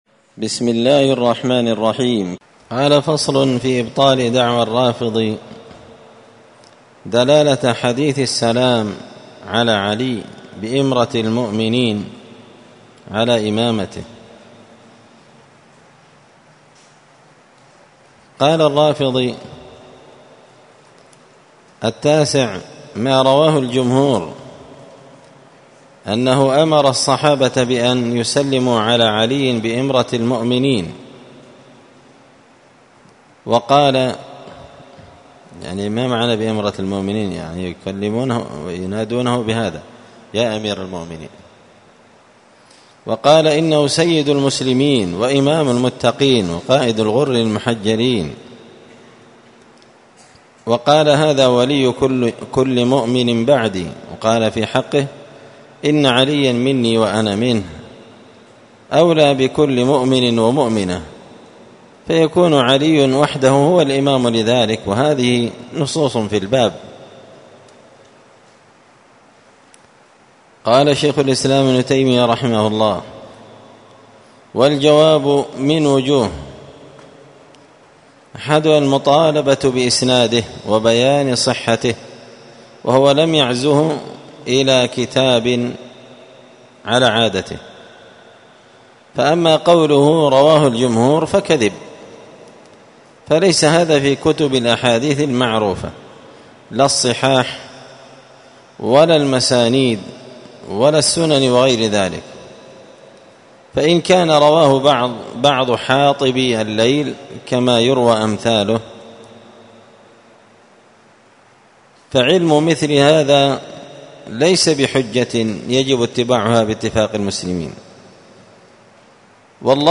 الأربعاء 28 صفر 1445 هــــ | الدروس، دروس الردود، مختصر منهاج السنة النبوية لشيخ الإسلام ابن تيمية | شارك بتعليقك | 9 المشاهدات